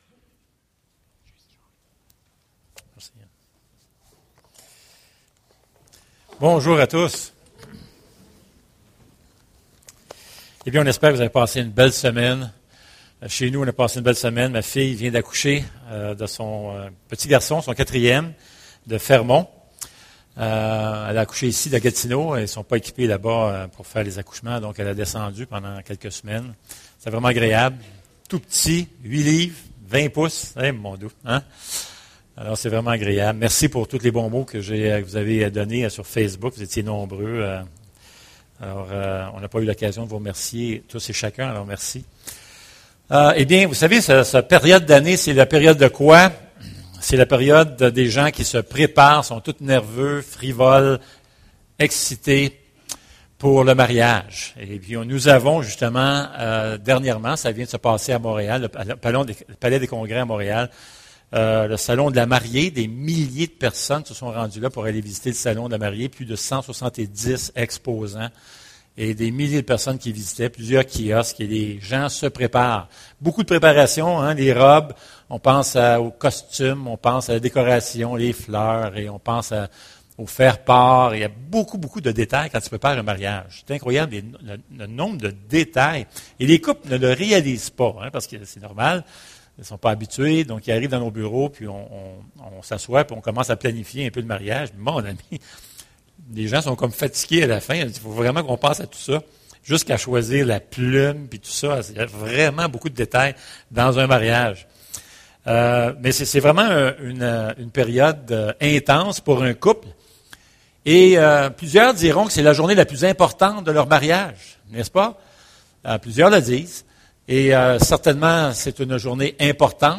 Passage: Éphésiens 5:23-33 Service Type: Célébration dimanche matin Cette année encore, le salon de la mariée a reçu des milliers de visiteurs au Palais des congrès de Montréal.